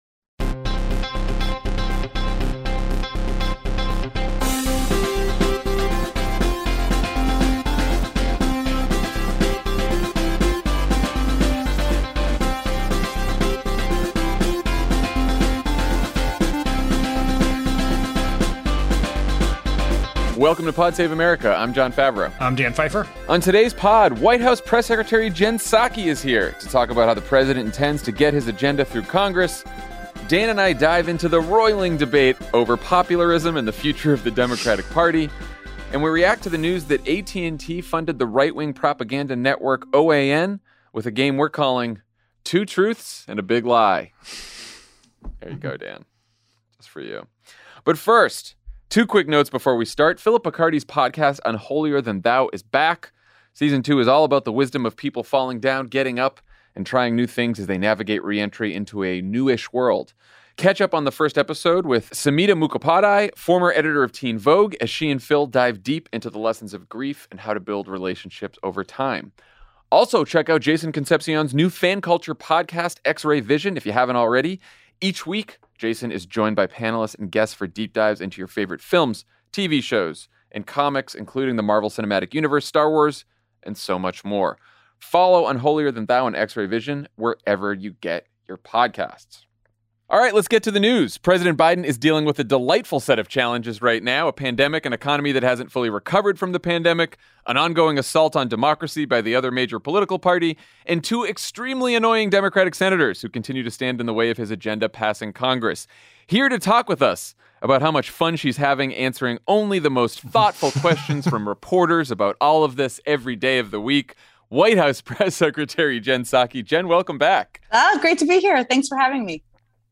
White House press secretary Jen Psaki joins Jon Favreau and Dan Pfeiffer to chat about all of the challenges in front of the Biden administration. Then Jon and Dan examine the debate over popularism and the direction of the Democratic Party and a new investigative report about AT&T’s role in propping up the right-wing propaganda network OAN.